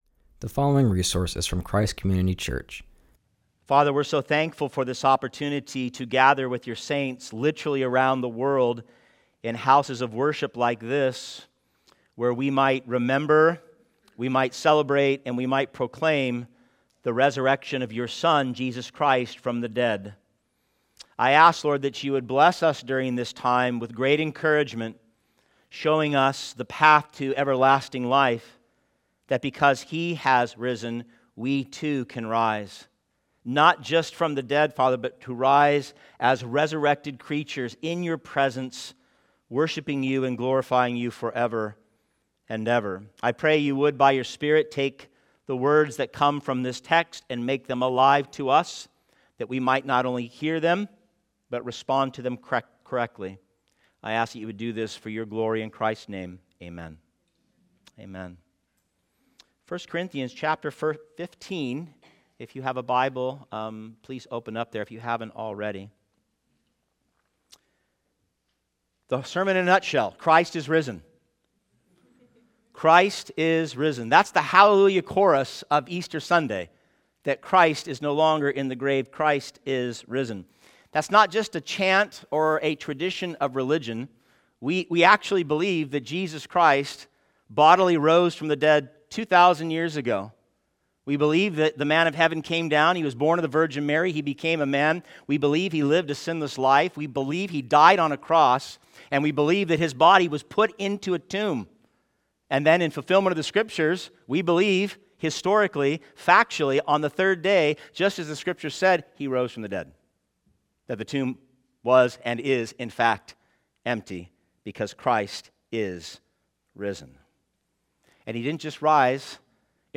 preaches on 1 Corinthians 15:42-49.